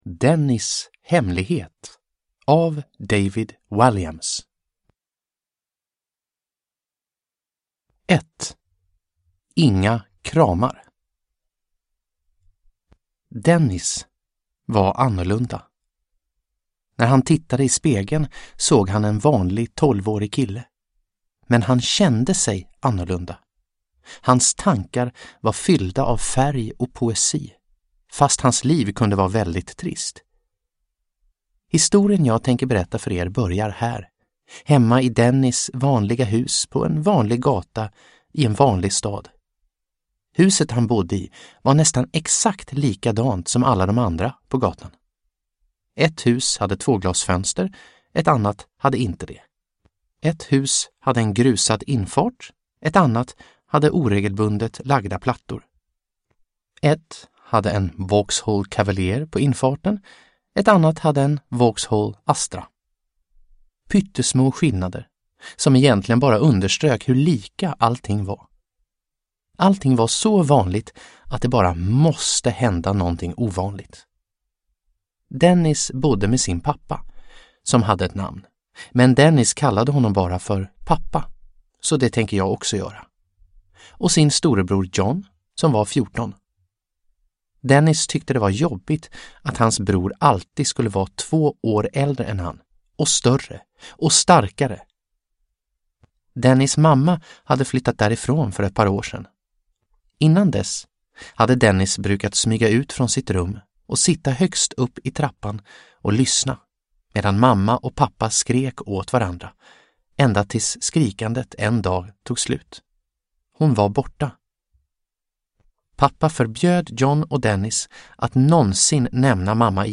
Dennis hemlighet – Ljudbok – Laddas ner